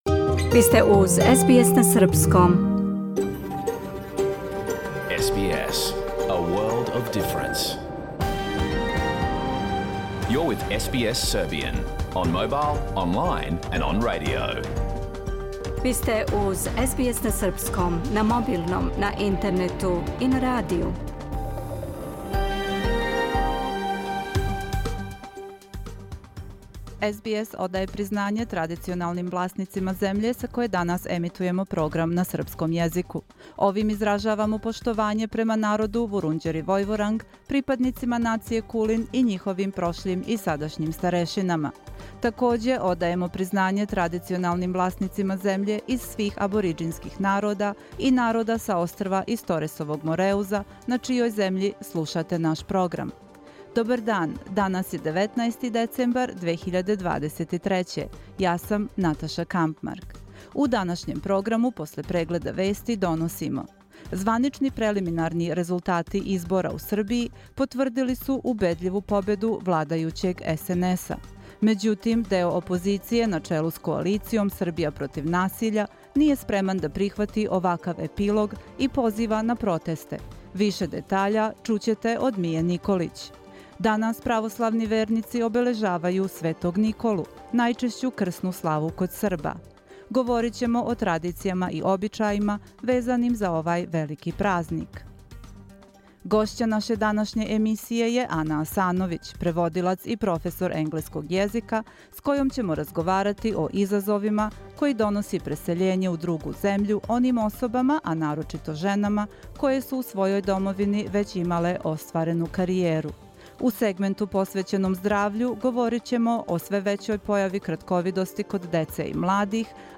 Програм емитован уживо 19. децембра 2023. године
Уколико сте пропустили данашњу емисију, можете је послушати у целини као подкаст, без реклама.